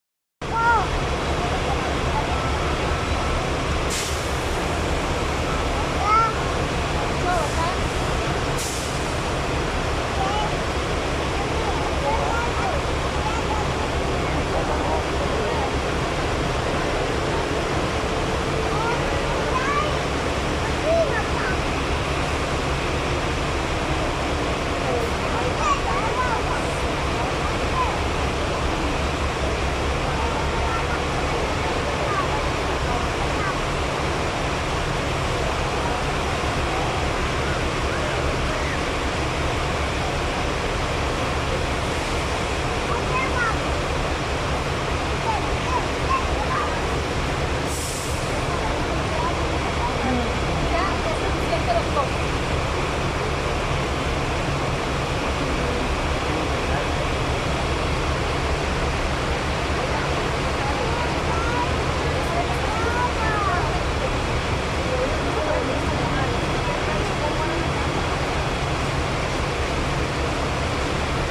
Un mapa sonoro es una técnica acústica para conocer los sonidos de un lugar, comunidad o ciudad; ubica los sonidos geográficamente.
Sonidos relajantes de una tarde de espera ALAJUELA